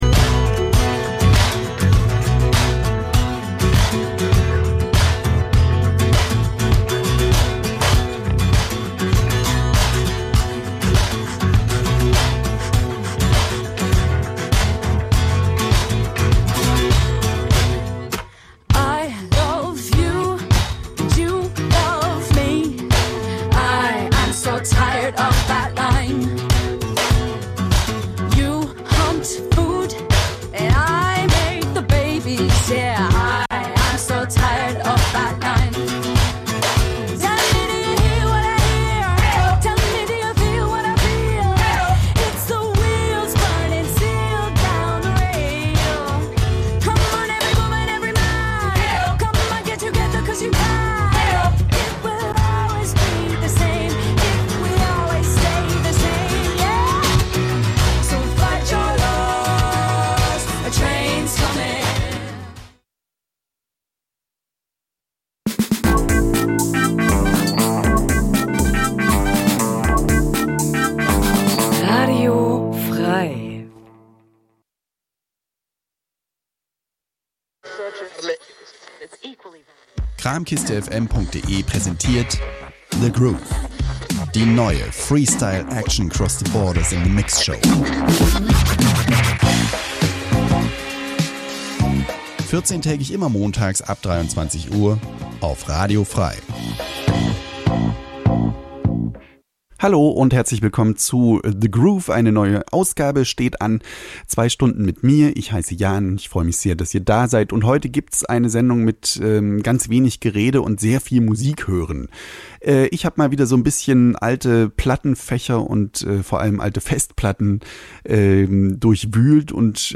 Experimental, Dub, Electronica, etc
House, Drum�n�Bass, Breaks, Hip Hop, ...